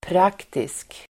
Uttal: [pr'ak:tisk]